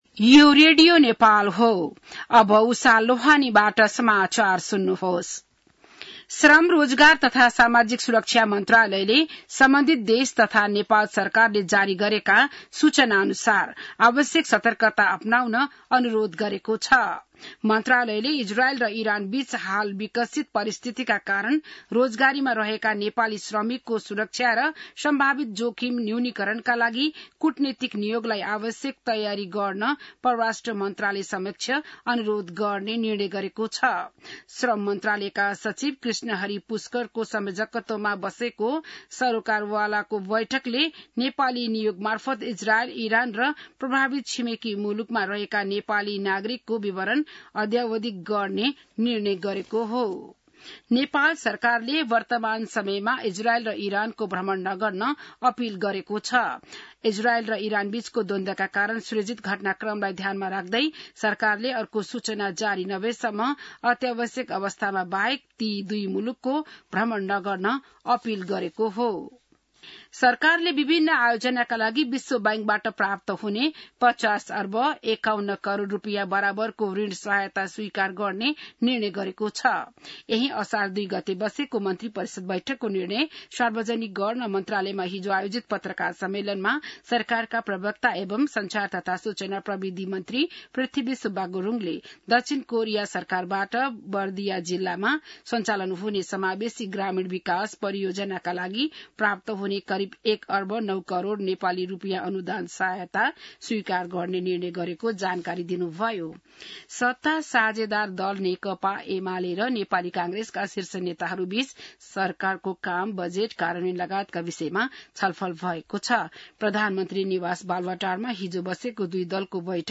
An online outlet of Nepal's national radio broadcaster
बिहान १० बजेको नेपाली समाचार : ५ असार , २०८२